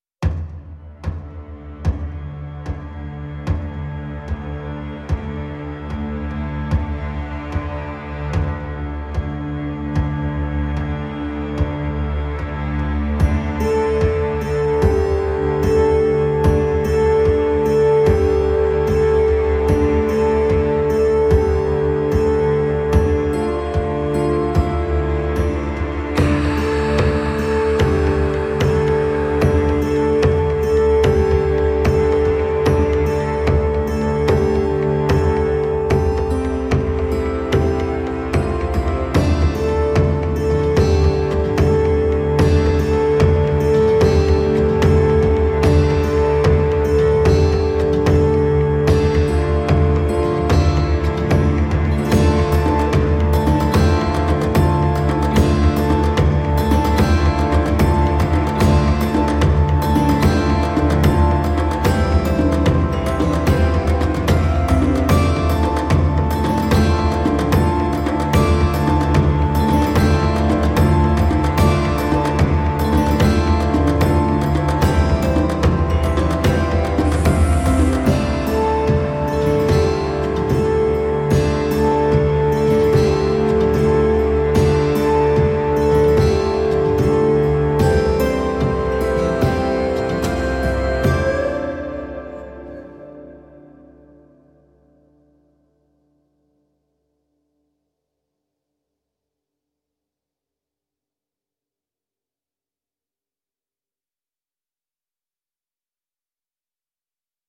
XPERIMENTA Kantele 是一个采样库，包含三种美丽的康特勒琴，这是芬兰的国家乐器。
XPERIMENTA Kantele 是在赫尔辛基的 Sibelius Academy 音乐技术部门的一个 105 平方米的录音室录制的，只使用顶级设备。
它还有一个“层”功能，可以给康特勒琴添加三种额外的乐器层：迷你合成器垫、钟琴和琵琶。